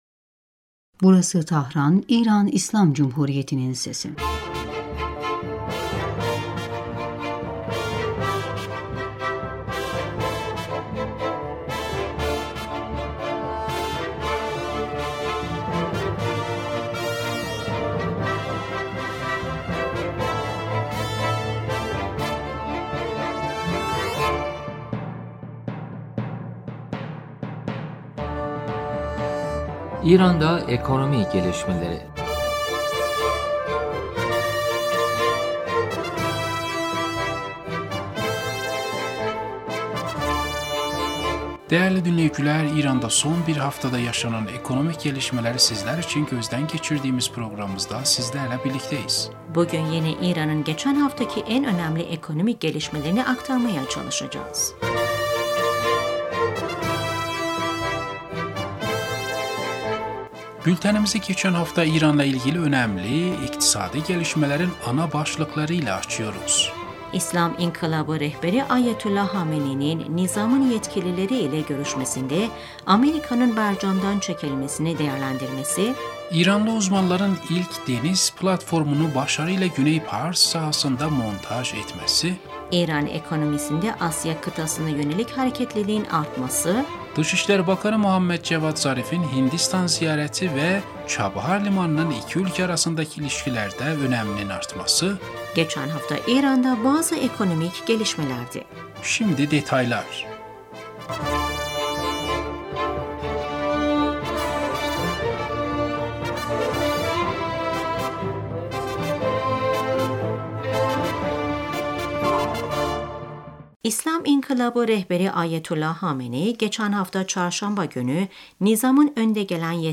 Bültenimizi geçen hafta İran’la ilgili önemli iktisadi gelişmelerin ana başlıkları ile açıyoruz.